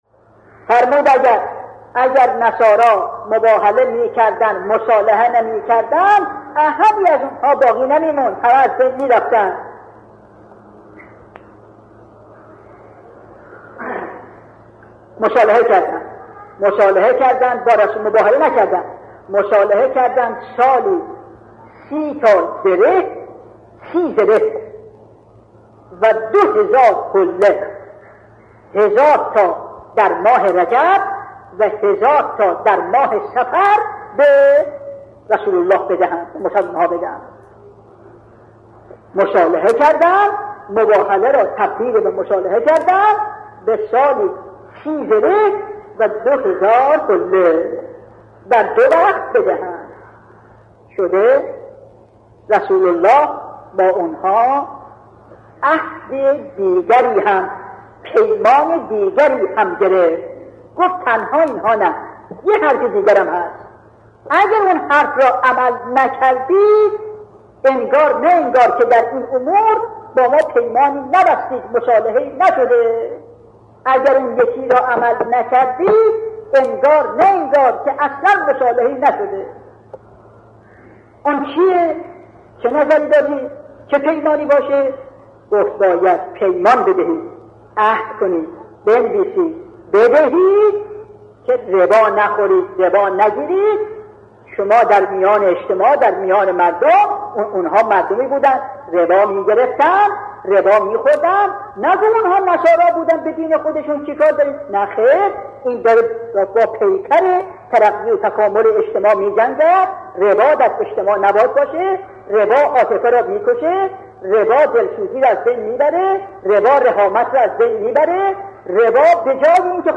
به گزارش خبرگزاری حوزه، مرحوم علامه حسن زاده آملی در یکی از سخنرانی های خود به موضوع «ربا و اثرات آن در جامعه» کرده‌اند که تقدیم شما فرهیختگان می شود.